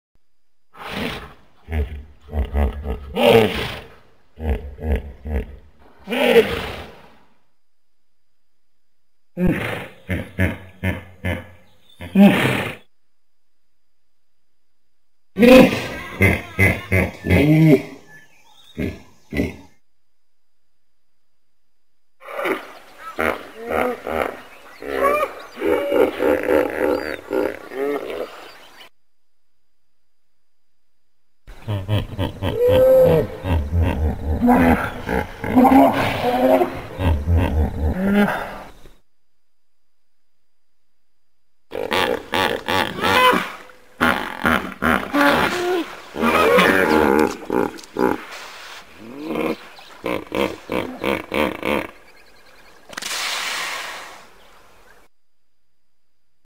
Погрузитесь в мир звуков бегемотов – от громкого рева до забавного фырканья!
Бегемот издает необычные звуки